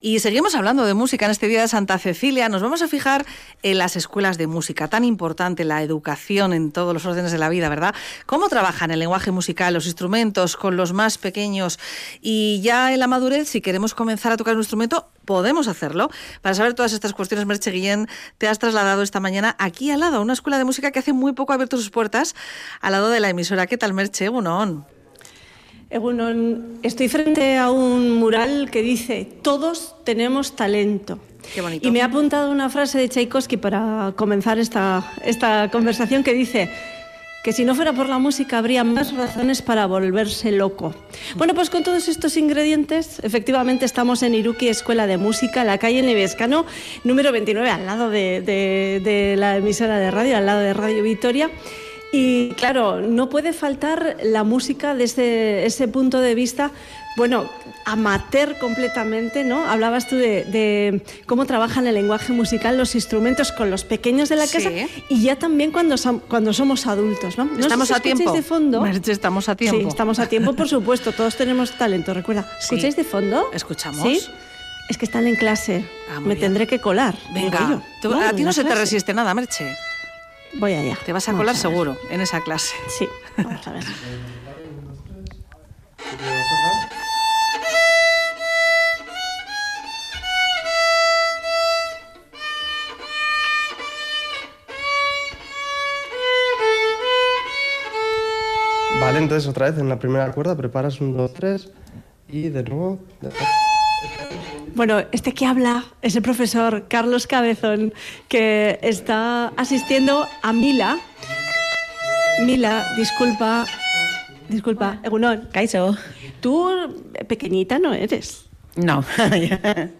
Audio: Aprender a tocar un instrumento no es cuestión de edad, sino de ganas. Hoy, Día de Santa Cecilia, patrona de la Música, nos acercamos a una escuela de música gasteiztarra.